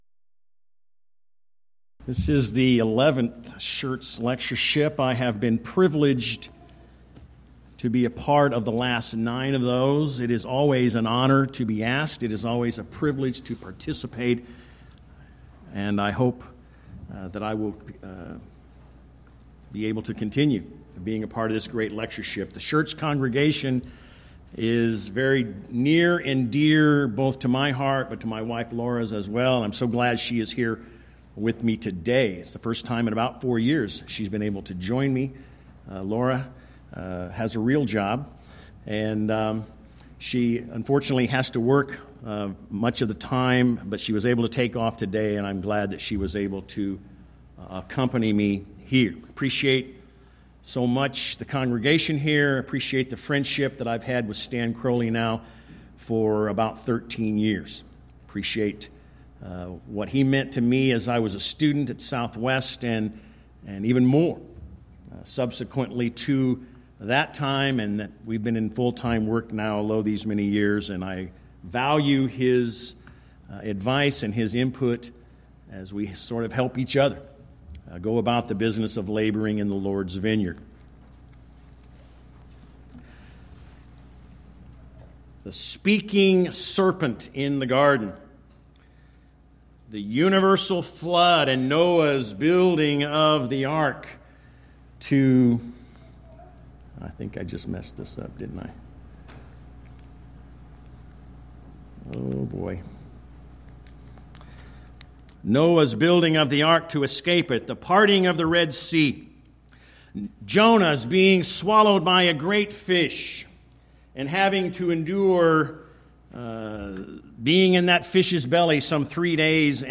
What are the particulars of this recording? Event: 11th Annual Schertz Lectures